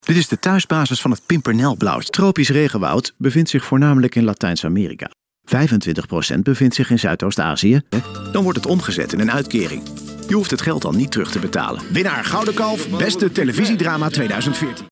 Dutch voice over